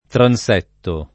[ tran S$ tto ]